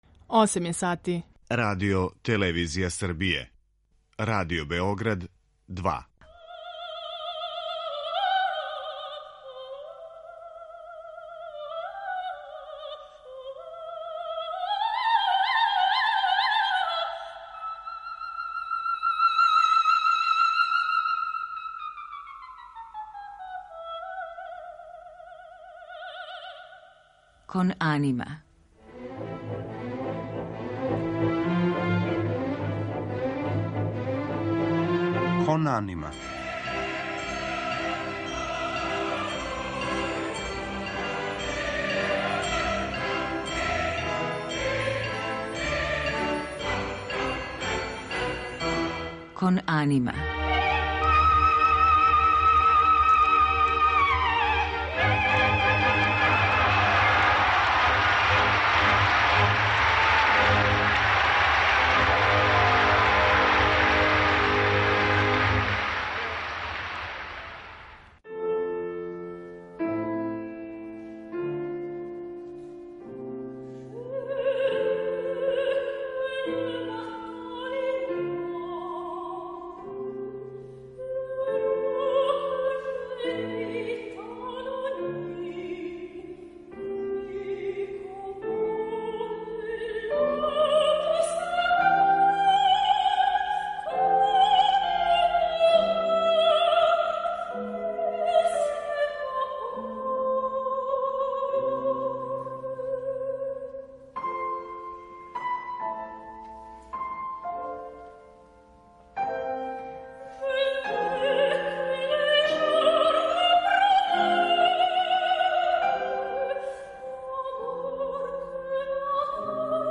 Немачка и српска критика њен глас описују као изразито експресиван, што једнако доказују наступи на оперској и концертној сцени. Разноврсни репертоар ове уметнице илустроваћемо интерпретацијама арија из опера „Кармен" Жоржа Бизеа и „Лукава мала лија" Леоша Јаначека, као и песмама за глас и клавир Габријела Фореа, Франца Листа и Љубице Марић.